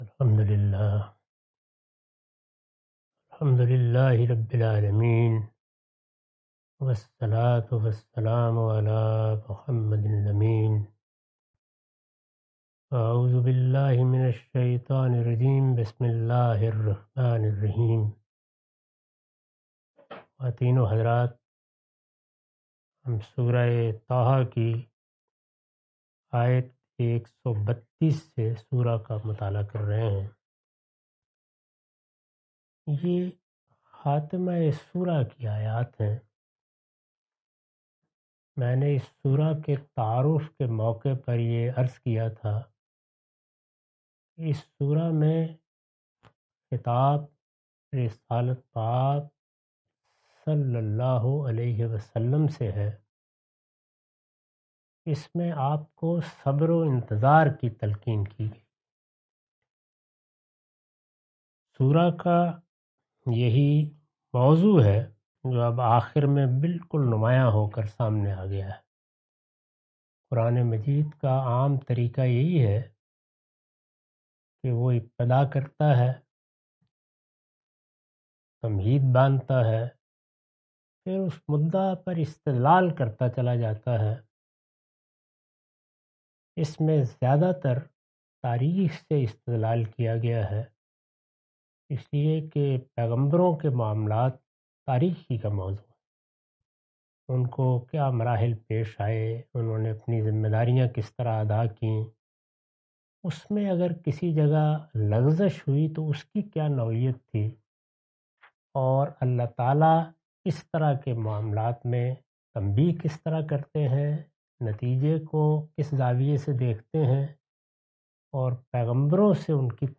Surah Taha A lecture of Tafseer-ul-Quran – Al-Bayan by Javed Ahmad Ghamidi. Commentary and explanation of verses 132-135.